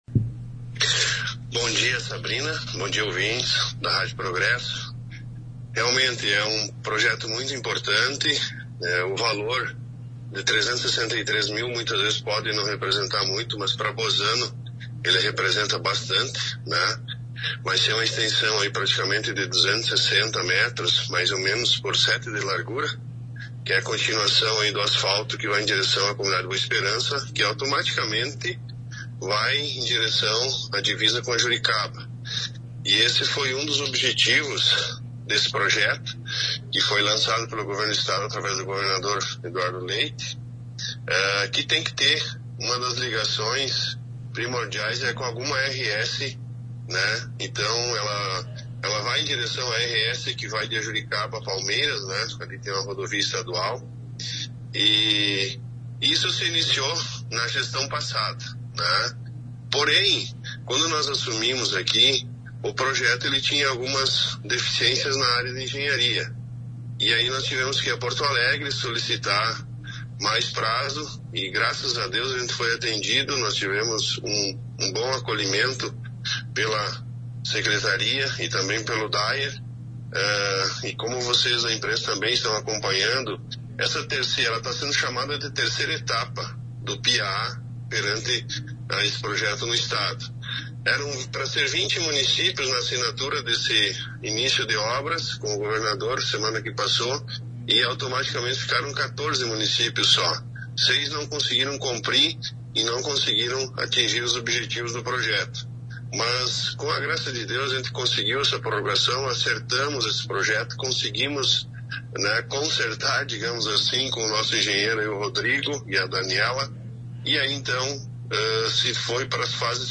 Abaixo você confere a entrevista completa com o prefeito de Bozano, que detalha sobre a execução do projeto: